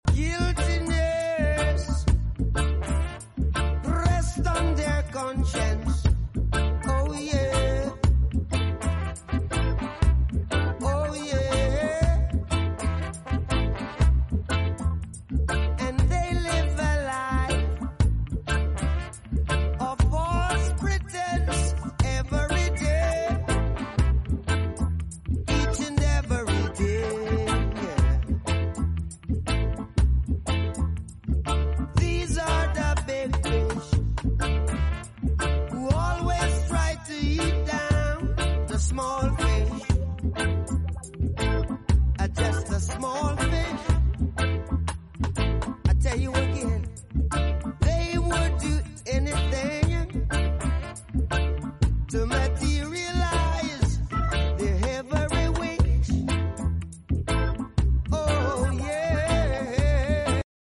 reggae groove